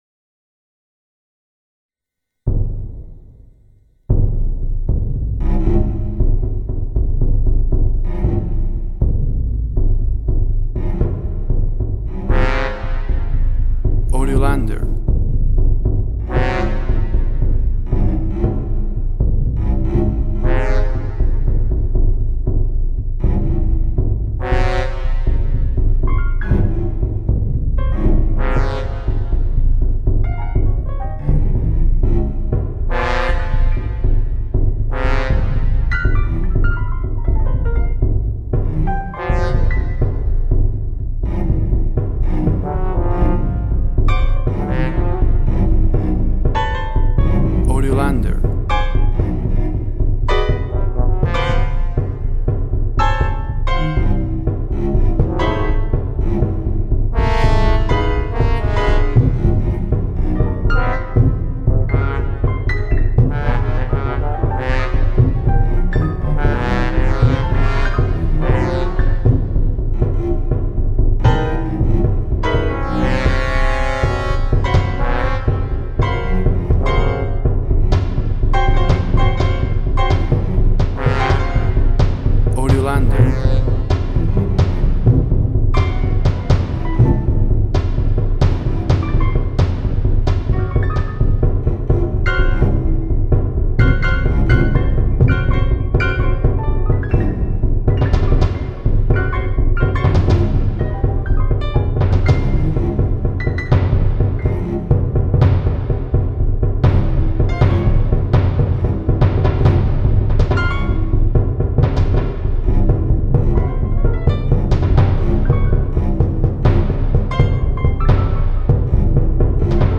Tempo (BPM) 86